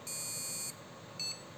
Was bedeutet folgender BIOS Beep-Code?
Ich hab mal den Speaker aus Interesse an mein Mainboard angesteckt und wollte Fragen, was der "Beep Code"(?!) im Anhang bedeutet. Es ist ein American Megatrends BIOS/UEFI.